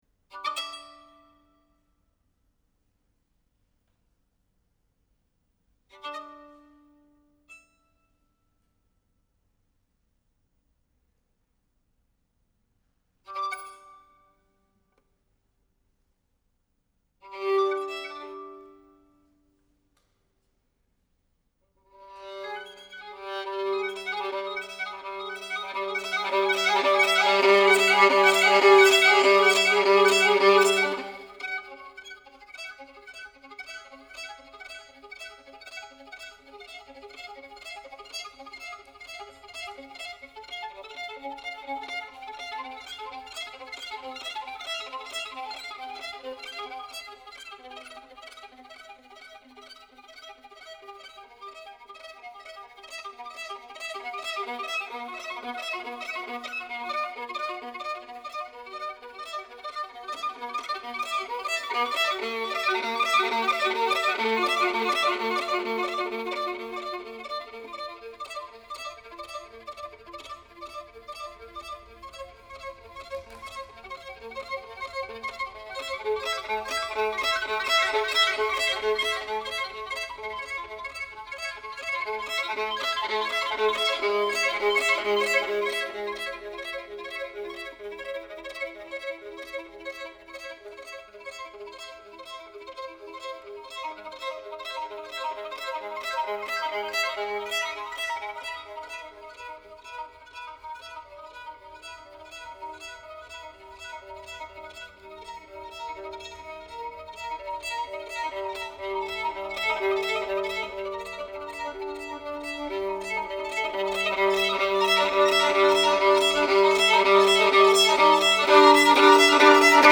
violine
analogue synthesizer